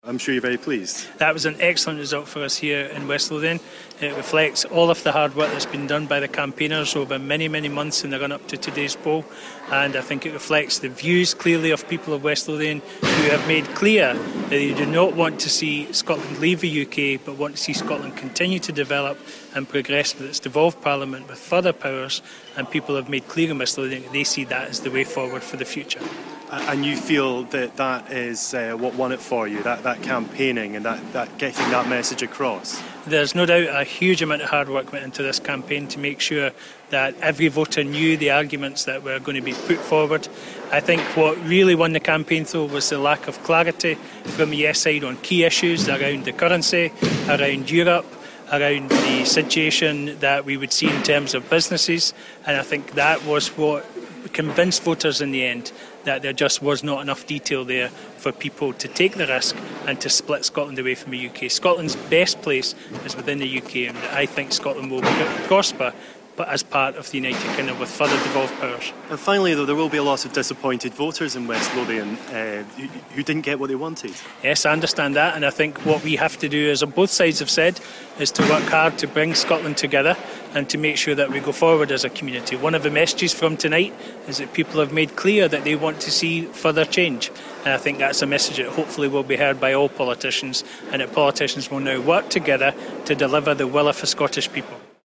It's a no in west Lothian with the better together campaign clinching just over 55% of the vote. Our reporter at the count in Livingston